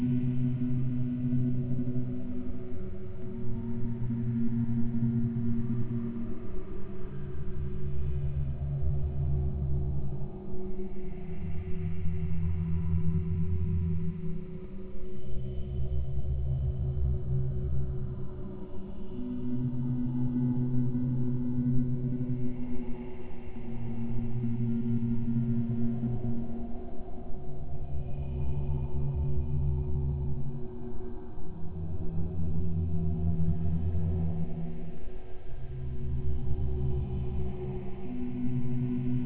Amber-Tone.ogg